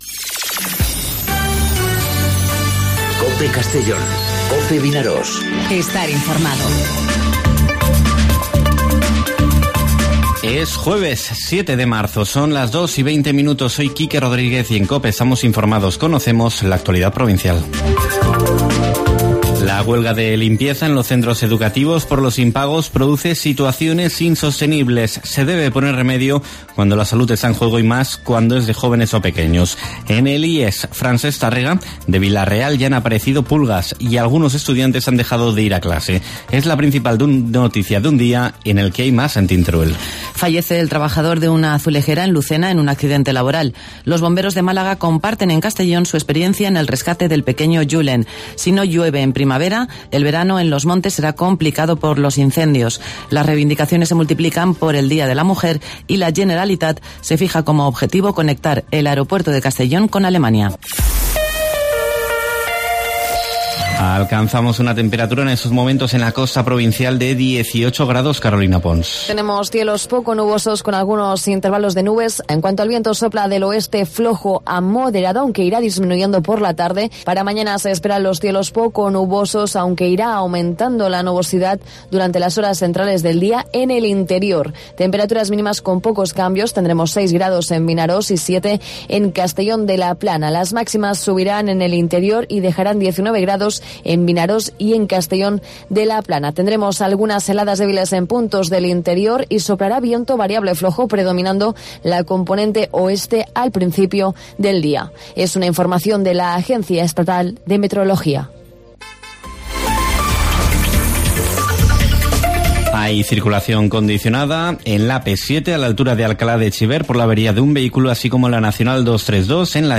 Informativo Mediodía COPE en Castellón (07/03/2019)